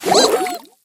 water_jess_ulti_01.ogg